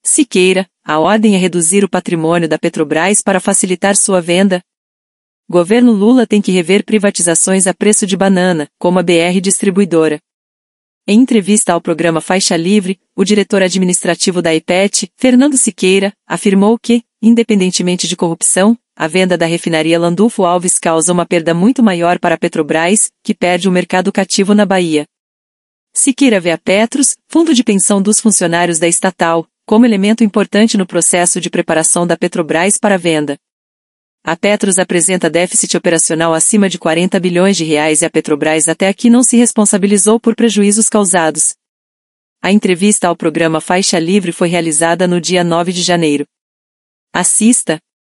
A entrevista ao Programa Faixa Livre foi realizada no dia 9 de janeiro.